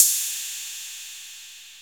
puredata/resources/808_drum_kit/hihats/808-Ride1.wav at 4120cef2659aa64a1ef0c2a18e90a604c7dfff99
808-Ride1.wav